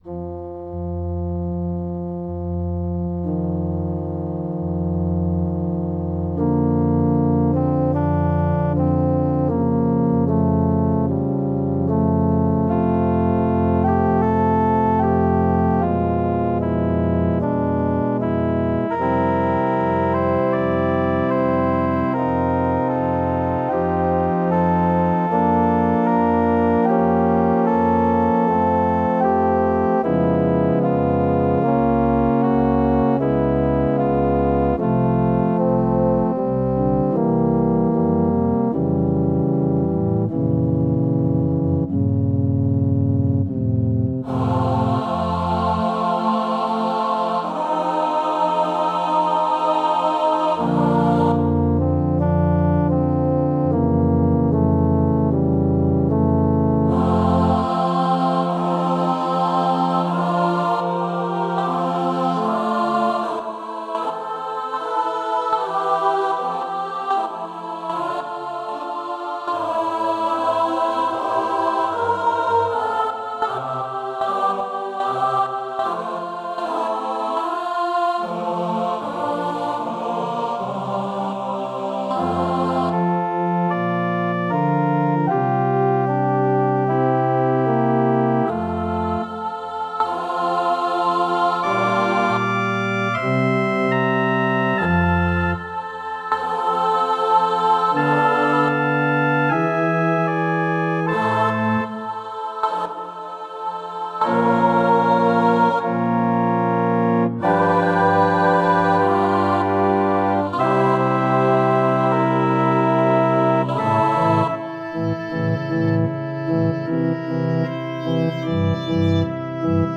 Mp3 files are provided for each movement, with each voice part emphasised, or with all voice parts at the same level.